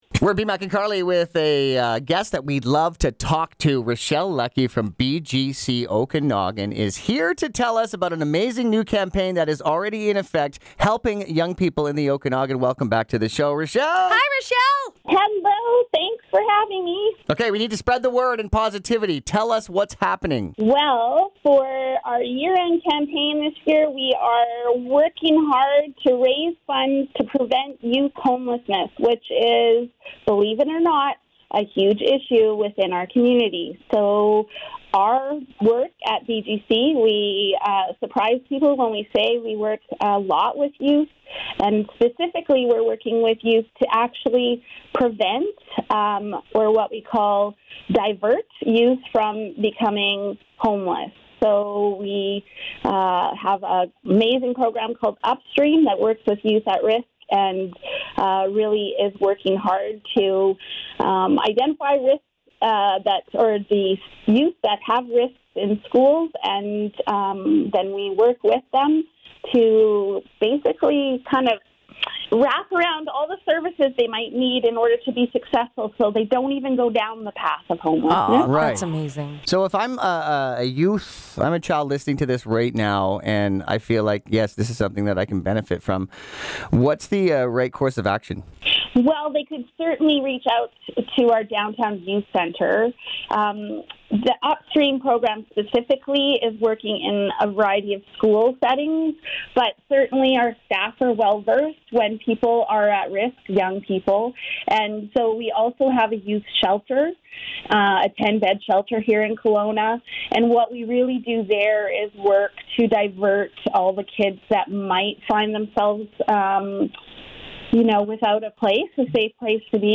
Listen Now: BGC Okanagan on air with Virgin Radio Kelowna